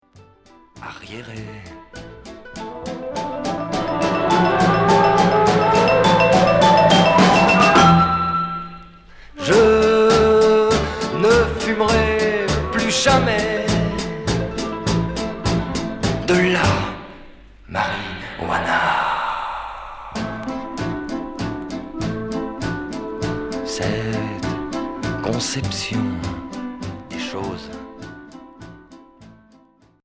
Pop folk psychédélique Premier 45t